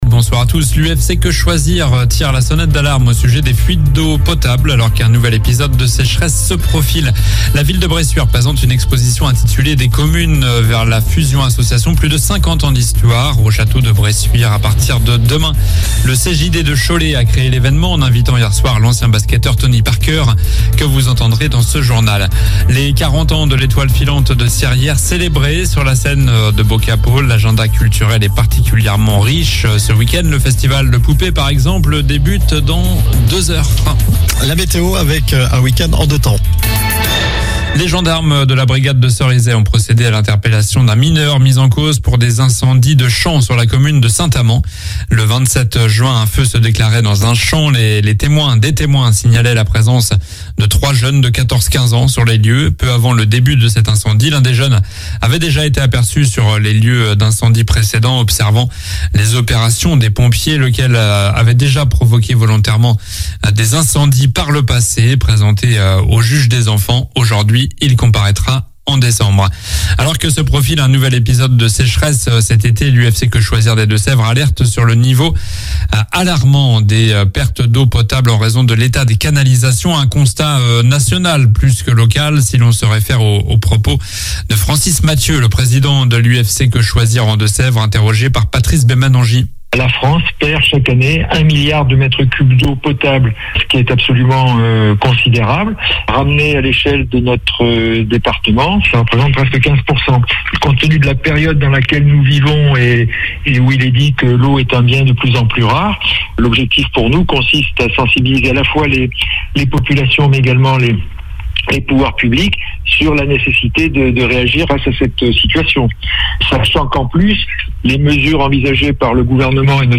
Journal du vendredi 30 juin (soir)